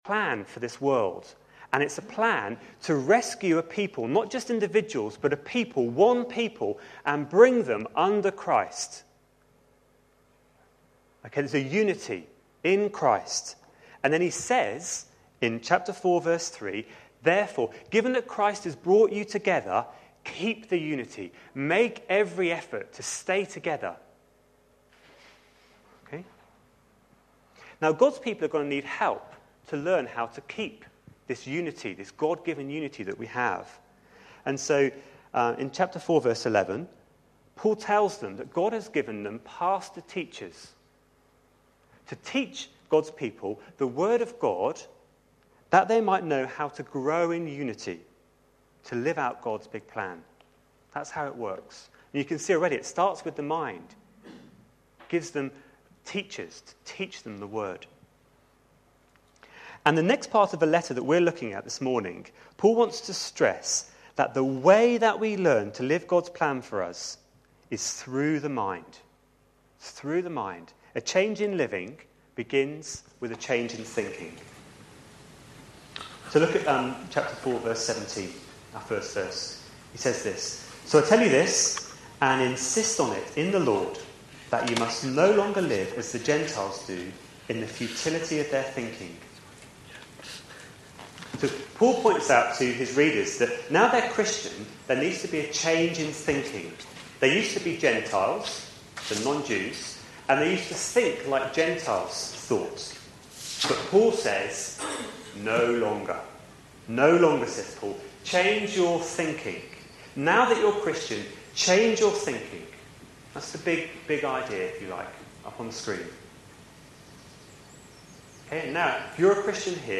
A sermon preached on 27th February, 2011, as part of our Ephesians series.